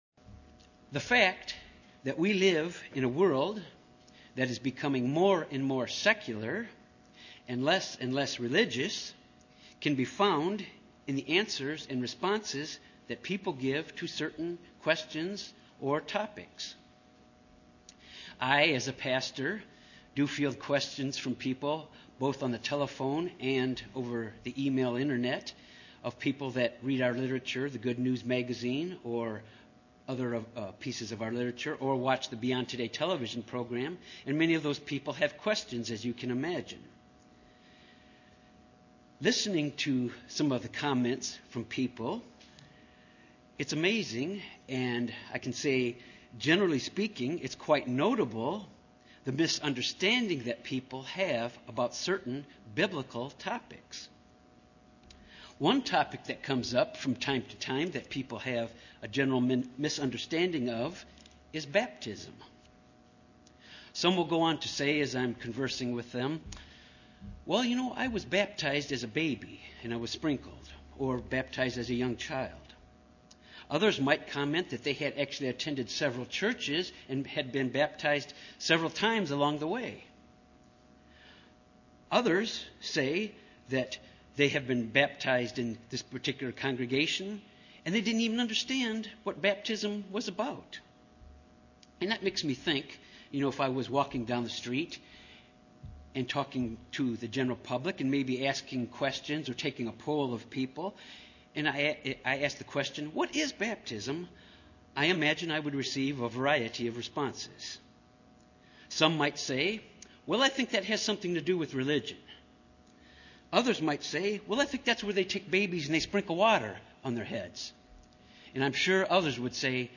Given in Little Rock, AR
This message deals with the important doctrine of baptism UCG Sermon Studying the bible?